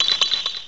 cry_not_vanillite.aif